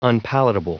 Prononciation du mot unpalatable en anglais (fichier audio)
Prononciation du mot : unpalatable